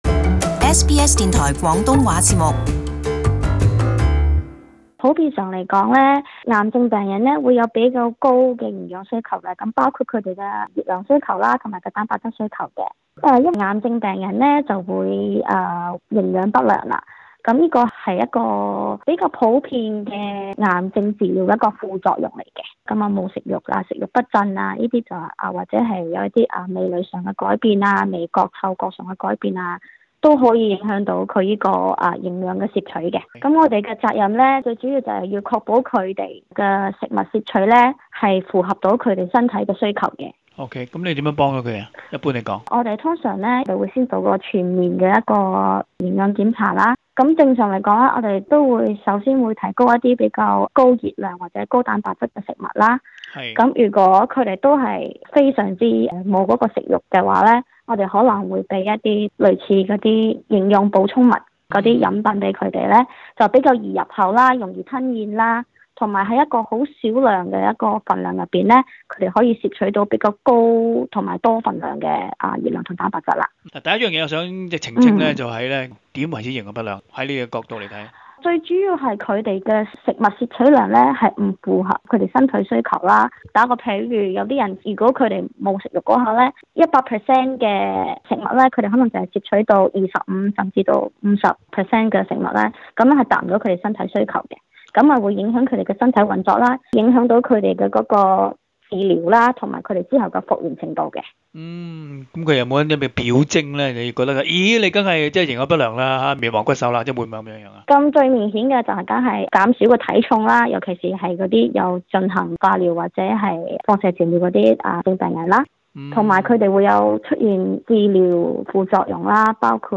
【社區專訪】